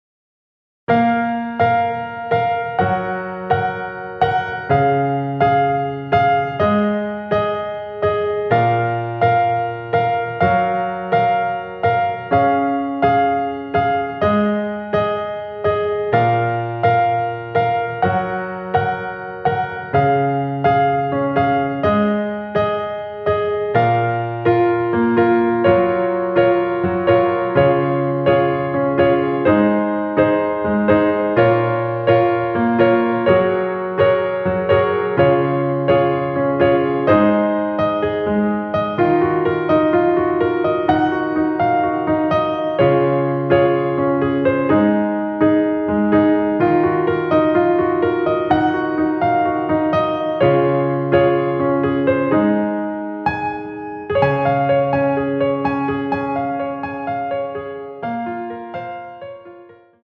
원키에서(+5)올린 MR입니다.
Db
앞부분30초, 뒷부분30초씩 편집해서 올려 드리고 있습니다.